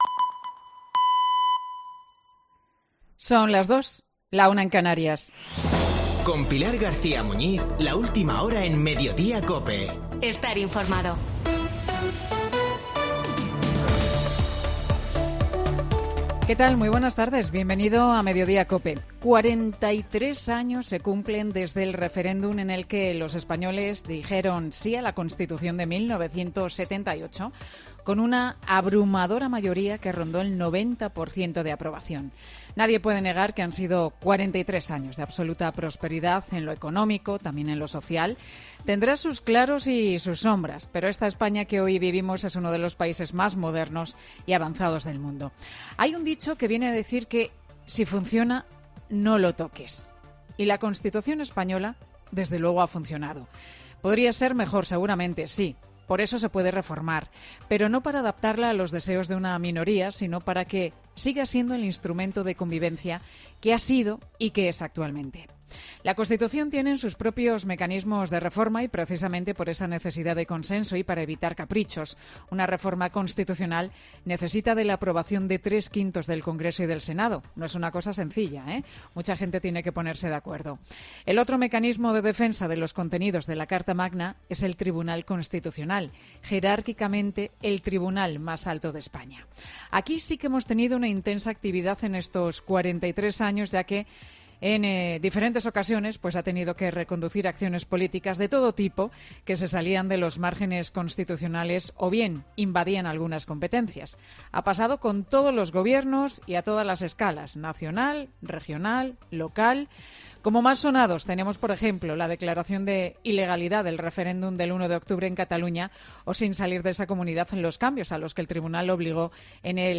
Boletín de noticias COPE del 6 de diciembre de 2021 a las 14.00 horas